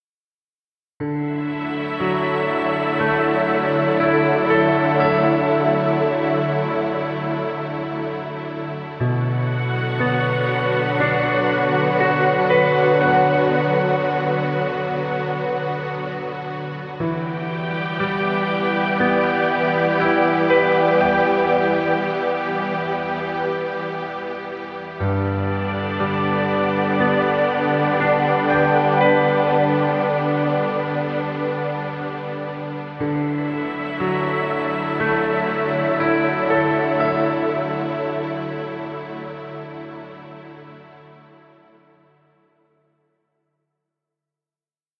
Relax music. Background music Royalty Free.